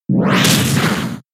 Headbutt
headbutt.mp3